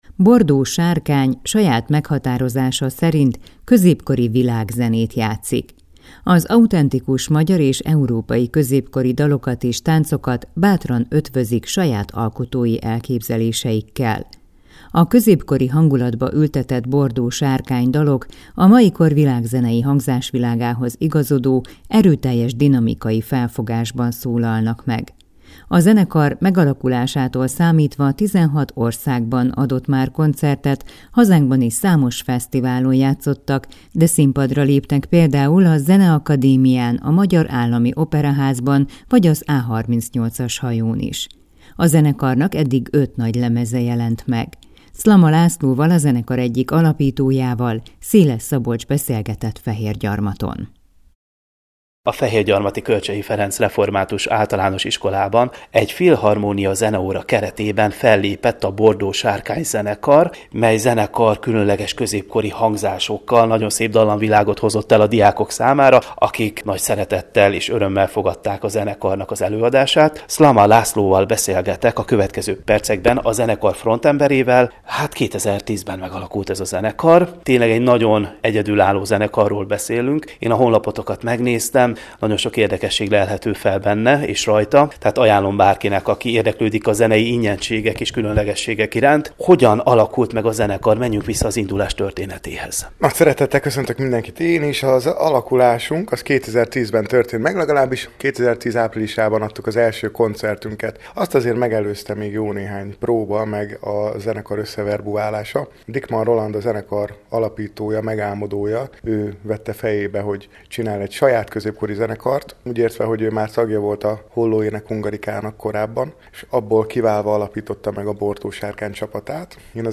Bordó Sárkány, saját meghatározása szerint „középkori világzenét” játszik. Az autentikus, magyar és európai középkori dalokat és táncokat bátran ötvözik saját alkotói elképzeléseikkel.